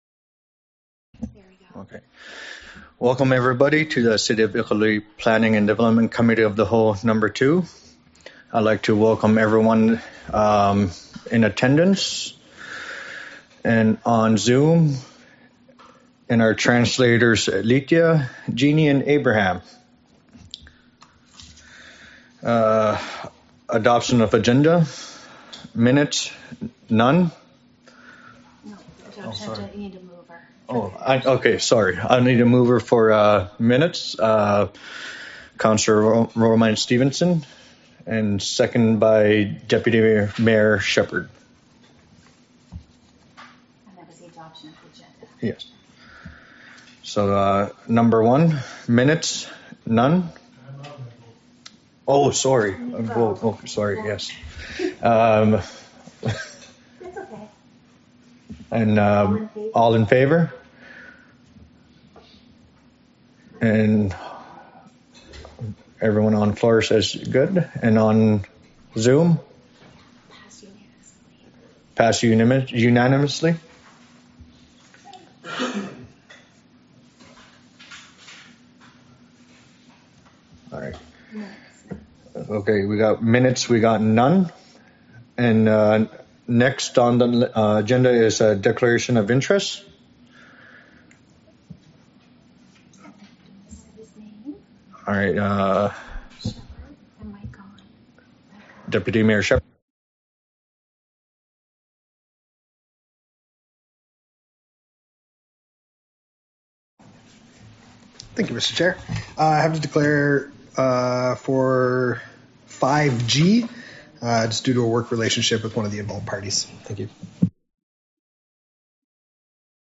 Planning and Development Committee of the Whole Meeting # 02 | City of Iqaluit